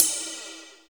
FLANGERIDEP.wav